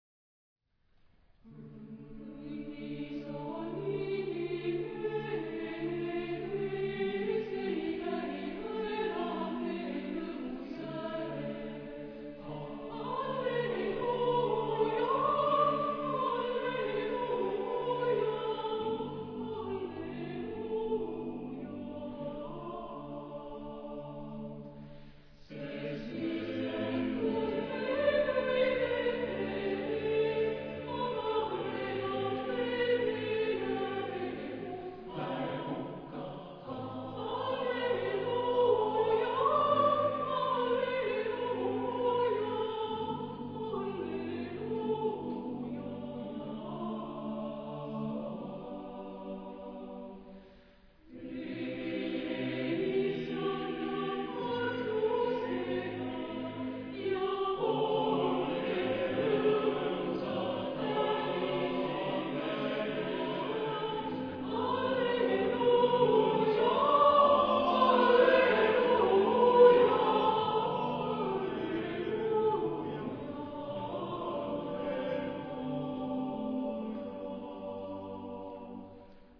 Genre-Style-Form: Sacred ; Psalm
Type of Choir: SATBB  (5 mixed voices )
Discographic ref. : Internationaler Kammerchor Wettbewerb Marktoberdorf